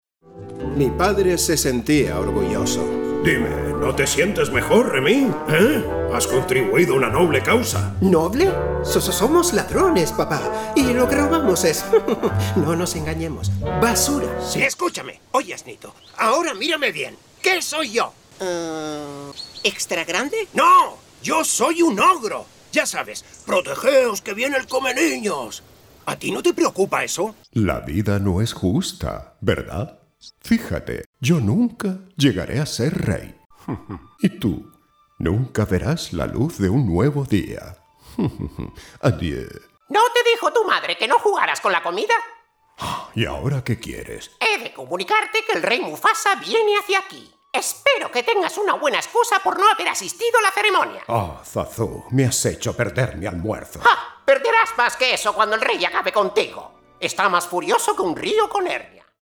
Cartoon/Animation
castilian
demo personajes animación.mp3